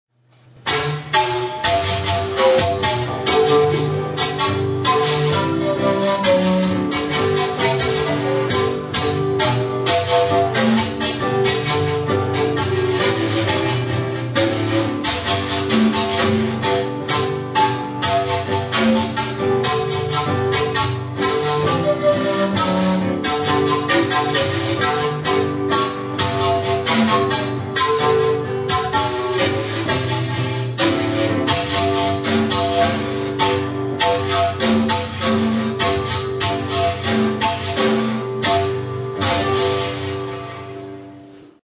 Aerófonos -> Flautas -> flauta de Pan
TOQUE DE TOYOS. Kañachama taldea.
HM Udazkeneko Kontzertua.
ZAMPOÑA; SIKU; Flauta de Pan